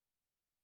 Silence.ogg